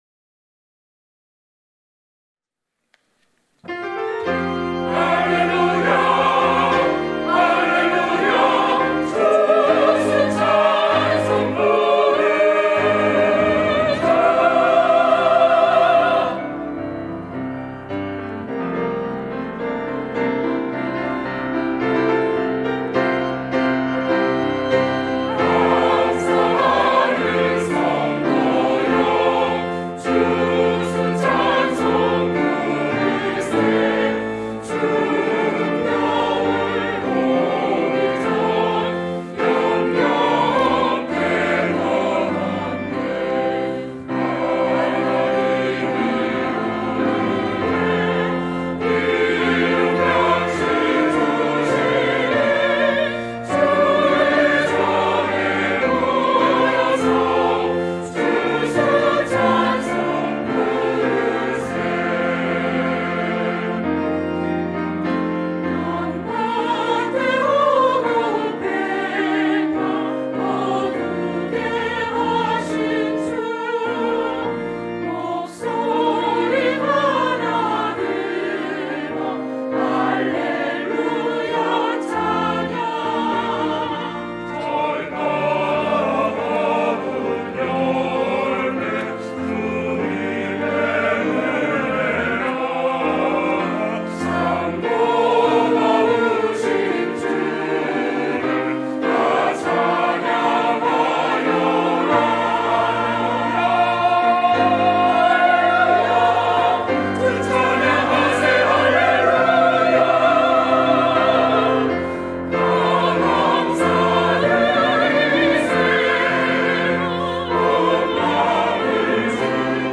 추수 감사절 찬송